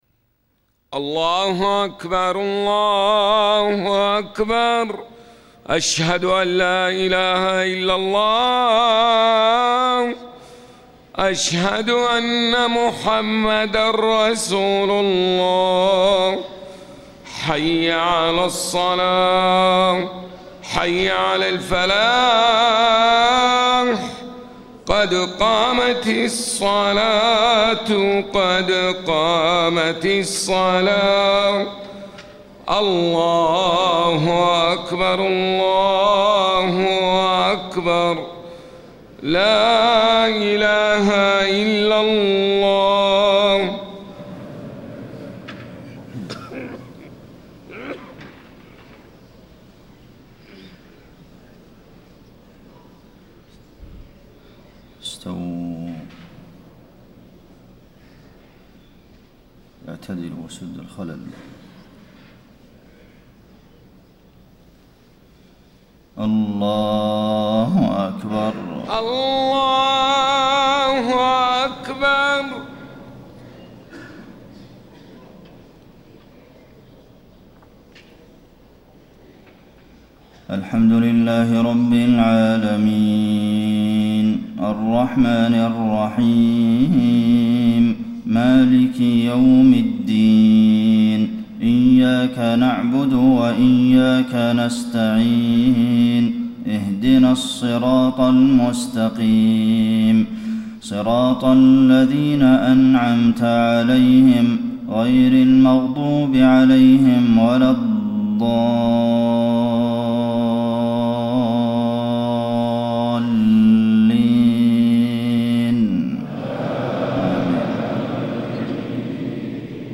صلاة الفجر 5-8-1434 من سورة الأعراف > 1434 🕌 > الفروض - تلاوات الحرمين